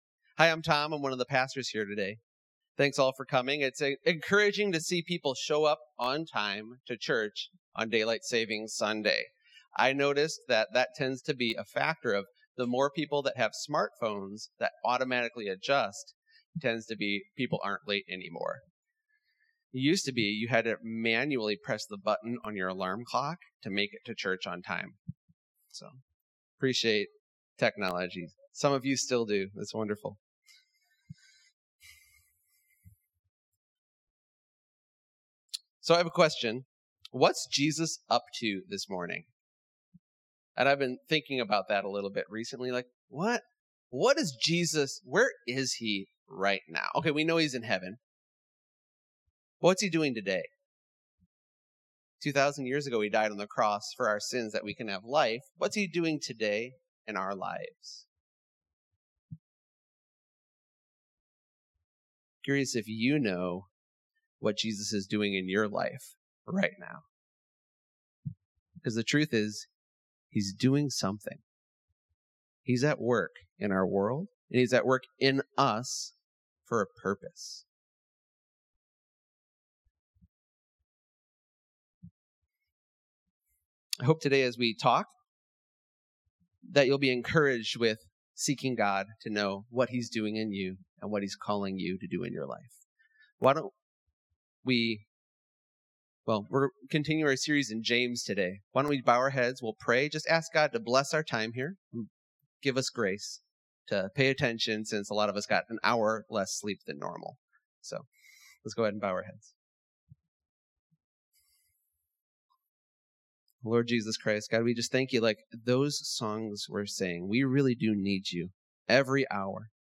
Mar 08, 2020 Faith & Works (03.08.2020) MP3 PDF SUBSCRIBE on iTunes(Podcast) Notes Discussion Sermons in this Series What saves us?